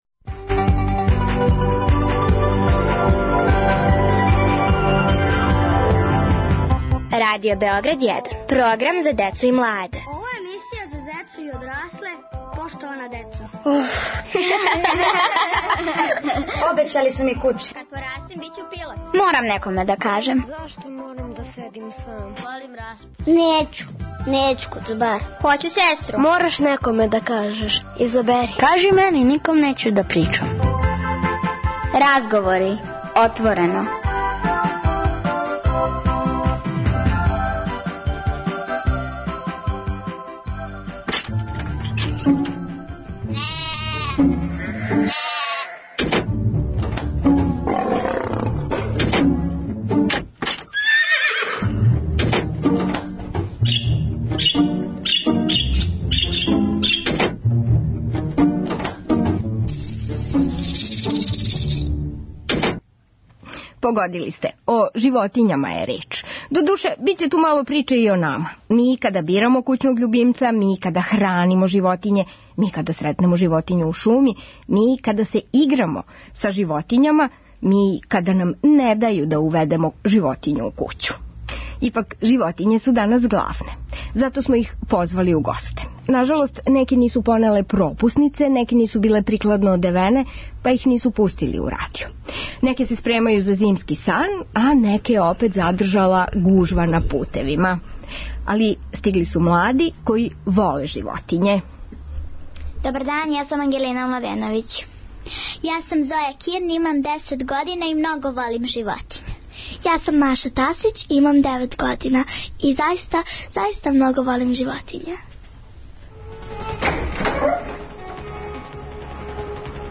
Разговори - отворено.